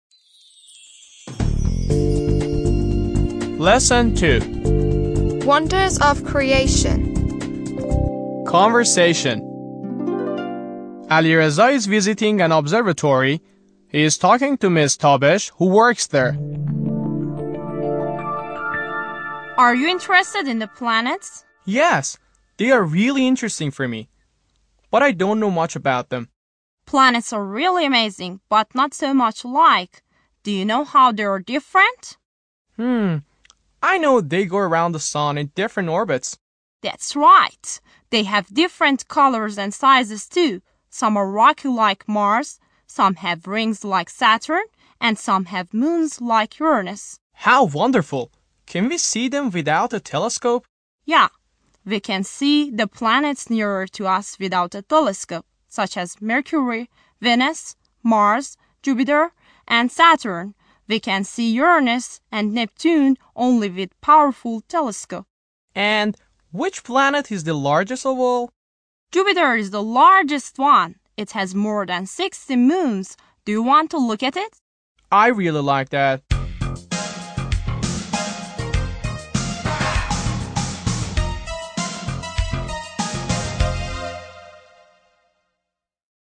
10-L2-Conversation
10-L2-Conversation.mp3